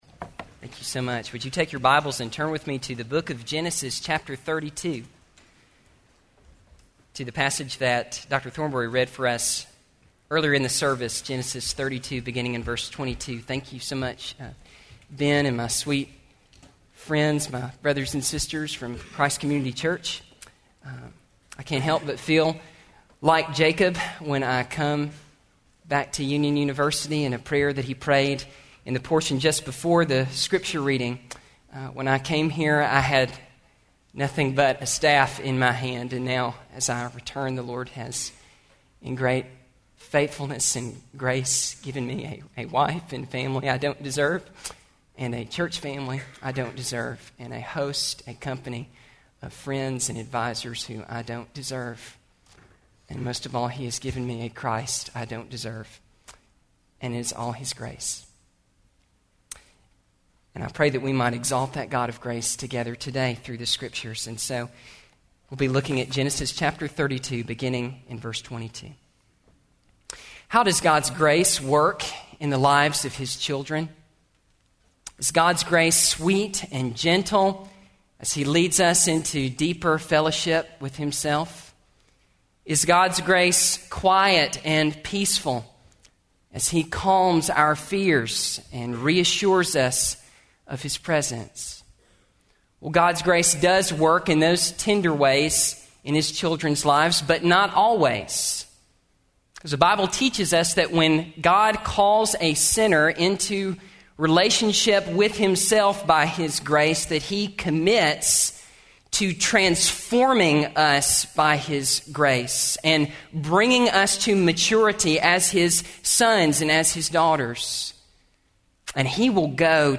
Address: “Grace at Work” from Genesis 32:22-32 Recording Date: Mar 14, 2012, 10:00 a.m. Length: 25:09 Format(s): MP3 ; Listen Now Chapels Podcast Subscribe via XML